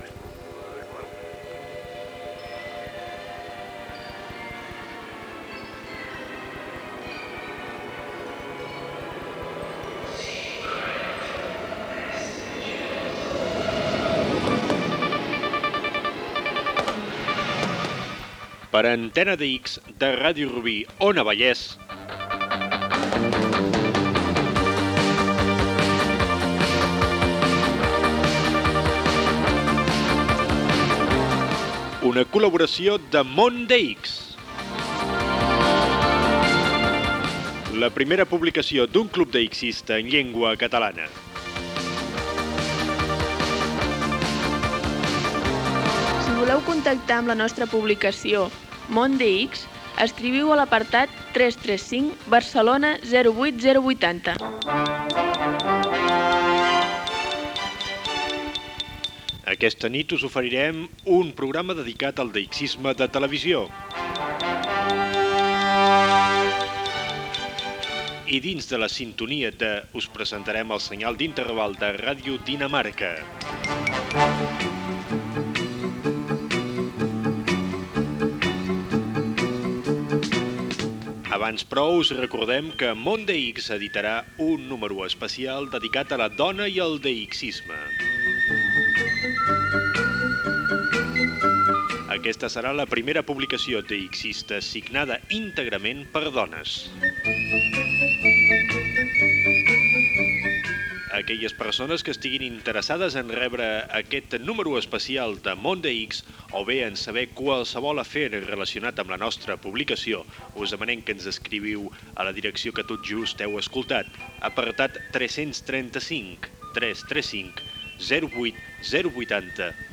9a2a315c24d6d752da2ea2b329d3f95ec99885af.mp3 Títol Ràdio Rubí Emissora Ràdio Rubí Titularitat Pública municipal Nom programa Antena DX Descripció Espai Món DX: número especial de la revista "Món DX" fet només per dones. Com fer fotografies de DX de televisió. Sintonia de Ràdio Dinamarca.